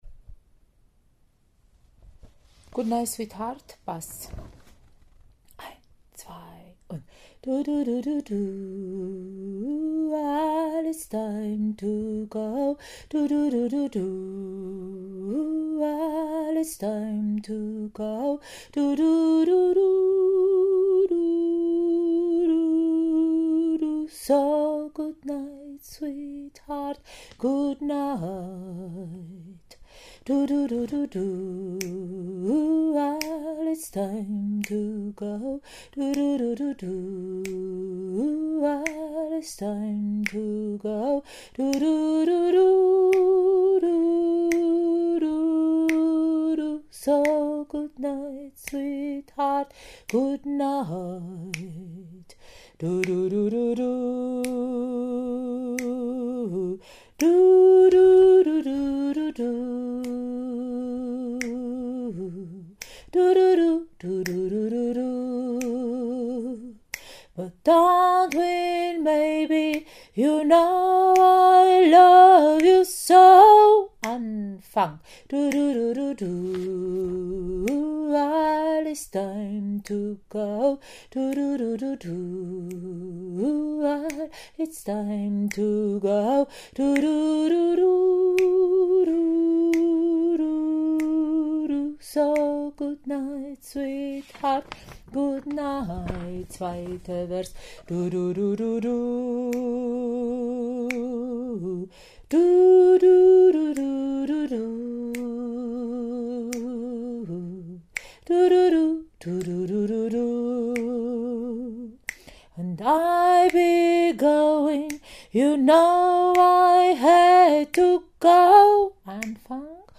Goodnight Sweetheart – Bass
Goodnight-Sweetheart-Bass.mp3